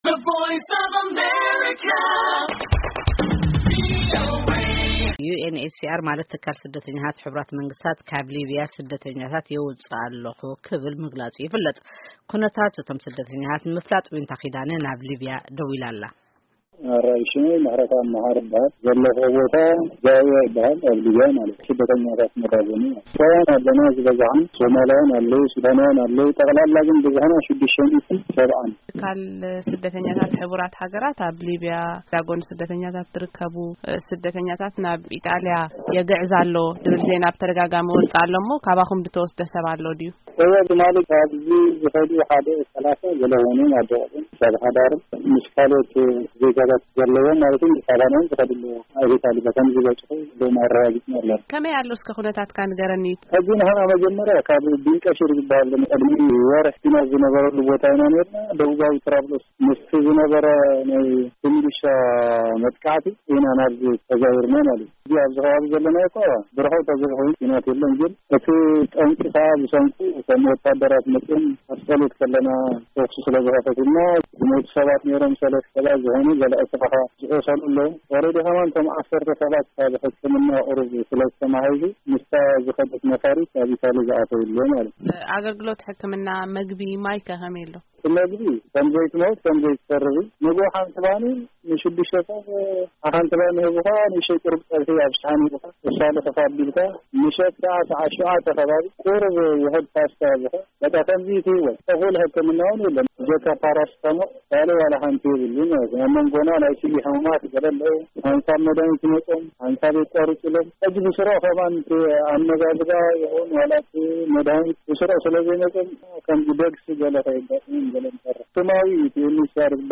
ብዛዕባ እዞም ስደተኛታትን ህልው ኩነታት እቶም ኣብ መዳጎኒ ማእከል ዘለው ስደተኛታት ዝምልከት ንክልተ ስደተኛታት ኣዘራሪብና ኣለና።